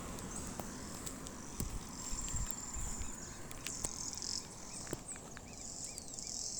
Carpinterito Común (Picumnus cirratus)
Nombre en inglés: White-barred Piculet
Localidad o área protegida: Dique El Cadillal
Condición: Silvestre
Certeza: Vocalización Grabada
Carpinterito-barrado.mp3